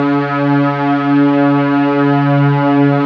STR STRING01.wav